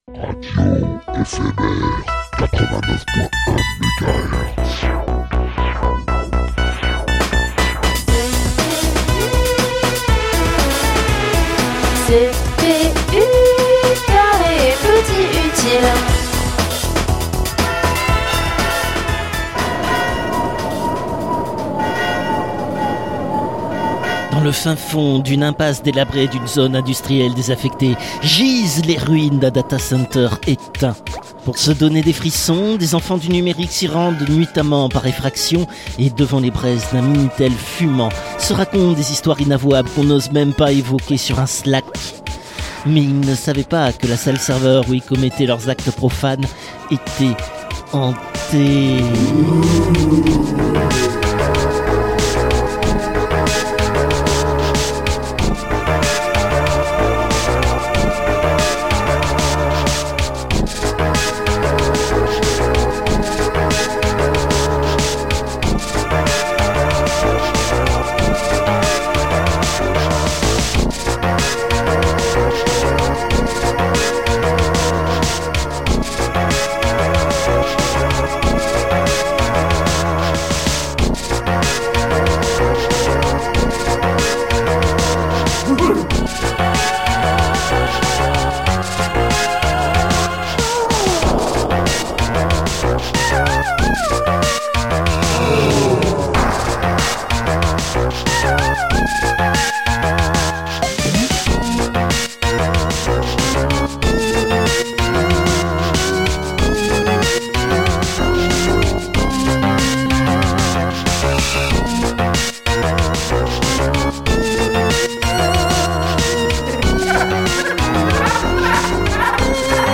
» humour Dans le fin-fond d'une impasse délabrée d'une Zone Industrielle désaffectée, gisent les ruines d'un data-center éteint.